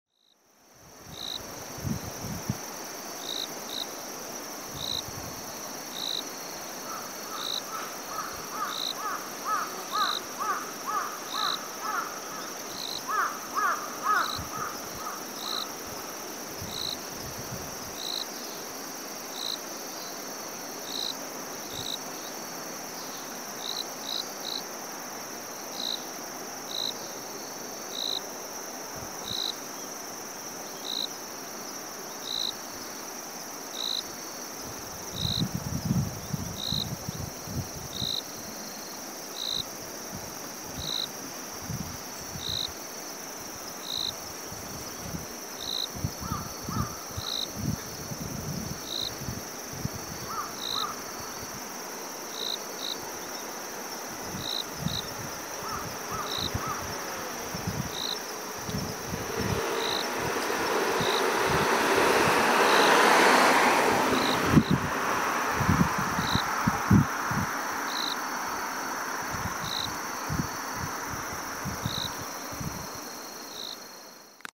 秋の音風景
Autum （↑クリックすると音がします。できればステレオ環境で聴いて下さい）
・中央のコオロギの声 ・左側からカラスの鳴き声 ・左側からかすかに小鳥の声 ・終盤左から右に走りぬける車の音 少なくともこれらの音を聞き取るとことができましたでしょうか？ 小鳥の声はよく耳をすまさないとわからないかもしれません。